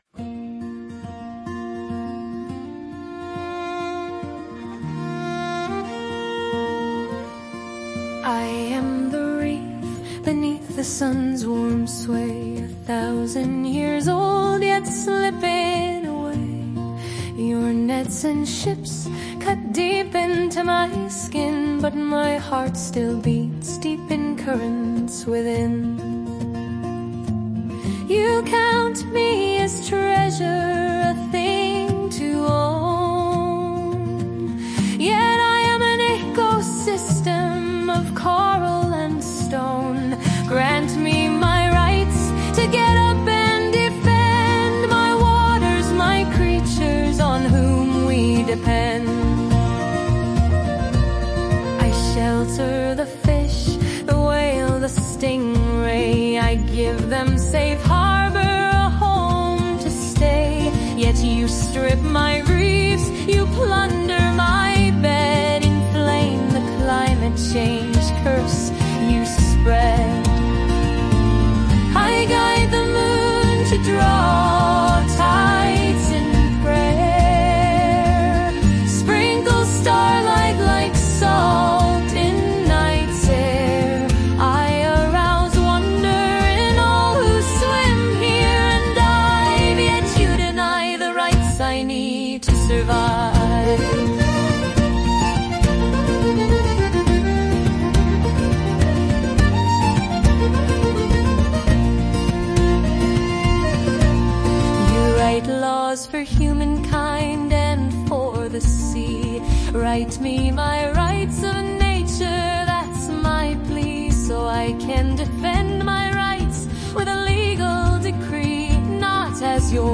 Singer > right voice & instrumentals for lyrics.